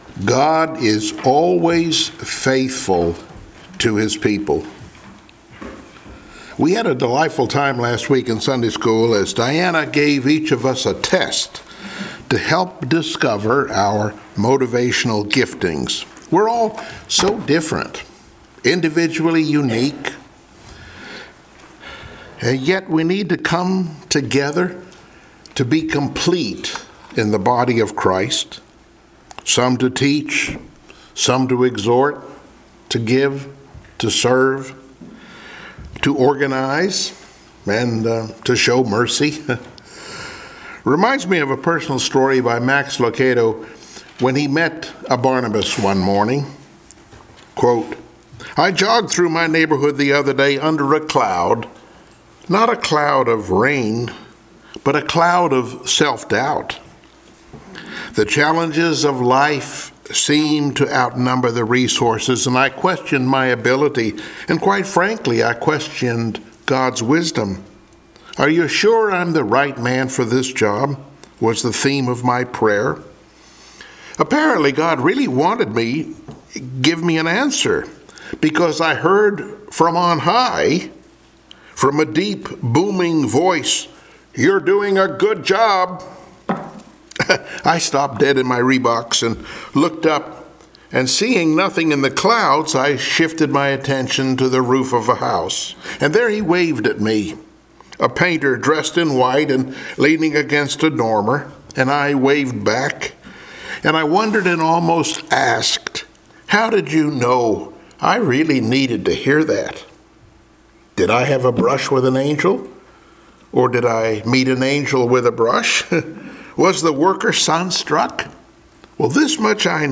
Passage: Esther 9 Service Type: Sunday Morning Worship